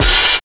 45 OP HAT 2.wav